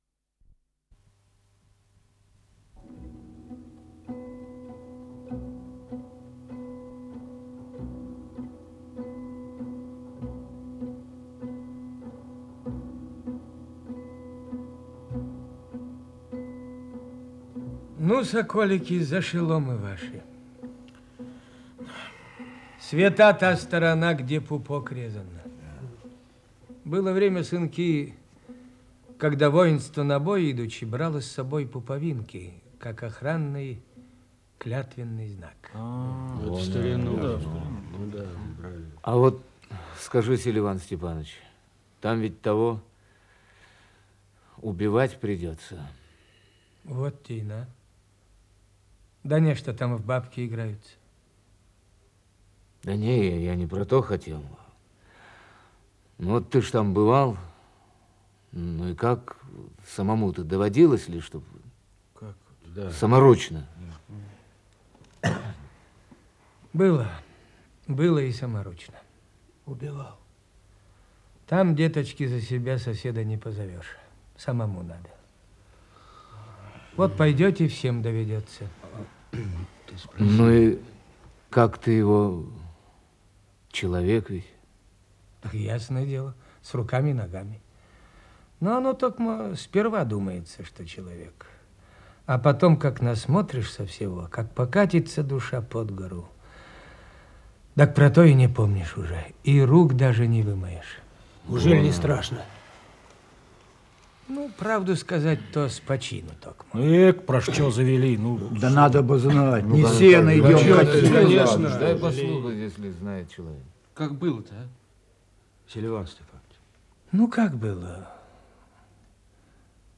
Исполнитель: Артисты ЦАТСА
Радиокомпозици спектакля